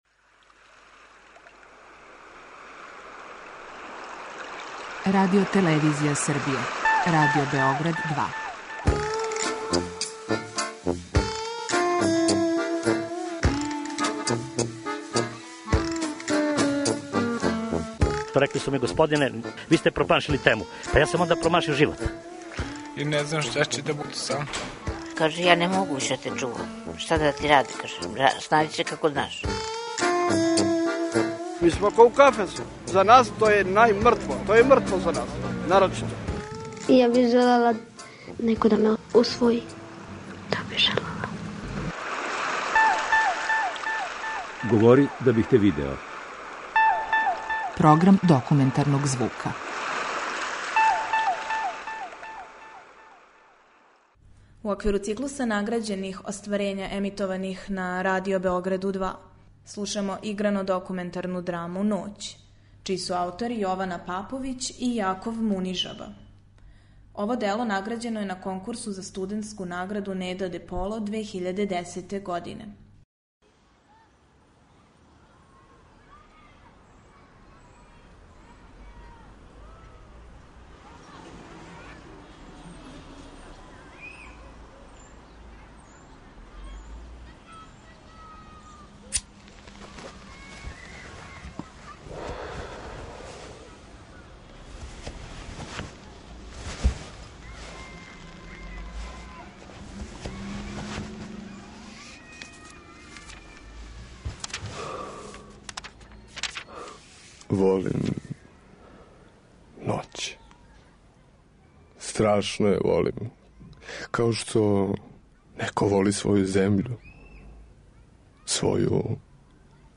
Документарни програм: Серијал награђених репортажа
Ово дело, награђено на конкурсу за студентски допринос радиофонији „Неда Деполо" 2010. године, настало је по мотивима истоимене проповетке Ги де Мопасана, уз коришћење документарног материјала снимљеног на београдским ноћним забавама.